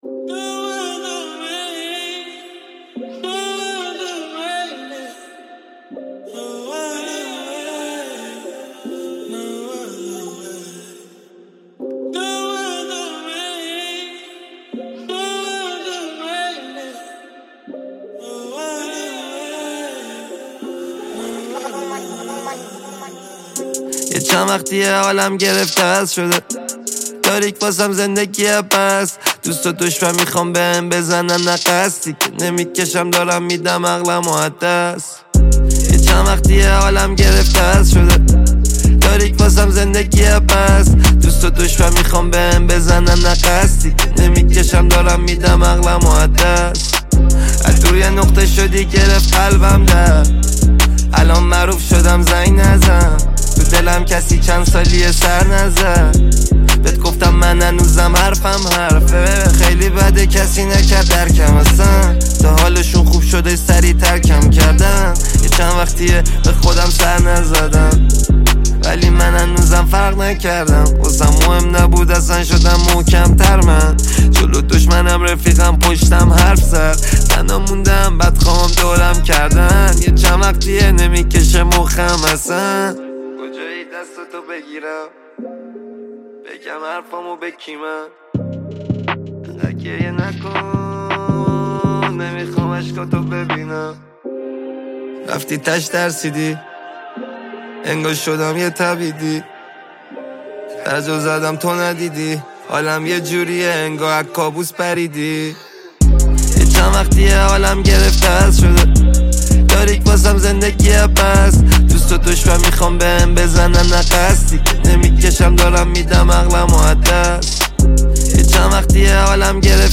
رپ فارسی
Persian RAP